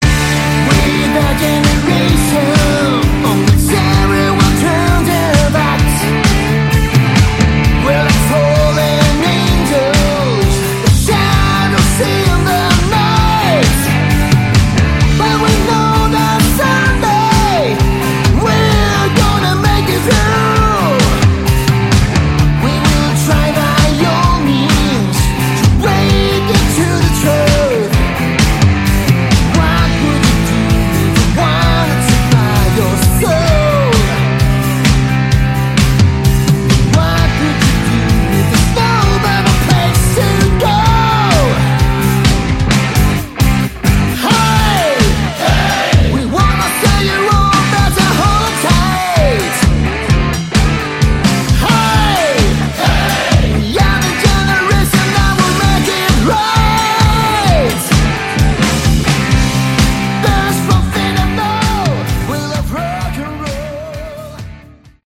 Category: Hard Rock
guitar
bass
lead vocals
drums